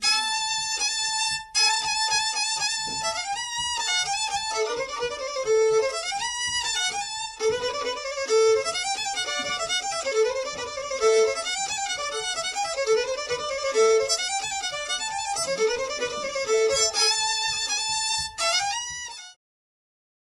Tę wypowiedź przyjęliśmy jako motto dla płyty prezentującej najstarsze archiwalne nagrania ludowej muzyki skrzypcowej ze zbiorów Instytutu Sztuki.